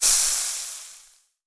cooking_sizzle_burn_fry_02.wav